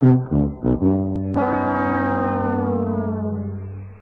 loser.ogg